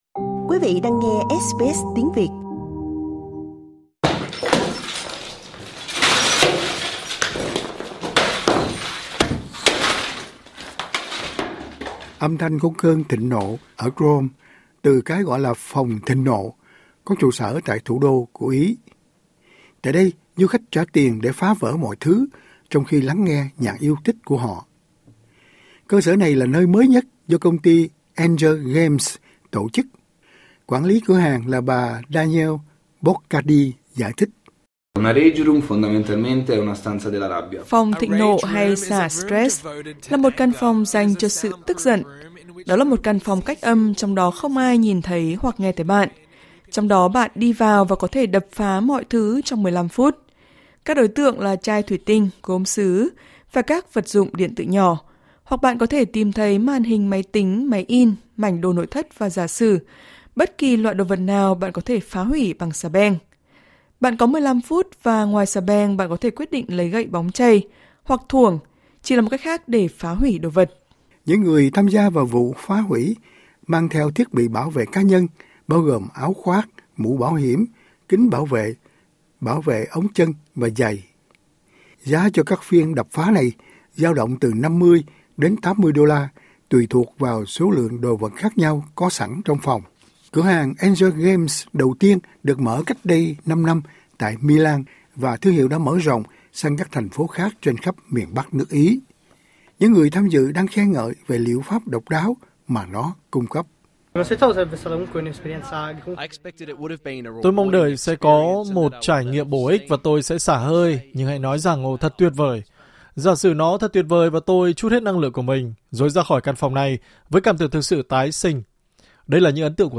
Âm thanh vang lên của cơn thịnh nộ ở Rome từ cái gọi là 'phòng thịnh nộ', có trụ sở tại thủ đô của Ý. Tại đây du khách trả tiền để phá vỡ mọi thứ, trong khi lắng nghe nhạc yêu thích của họ.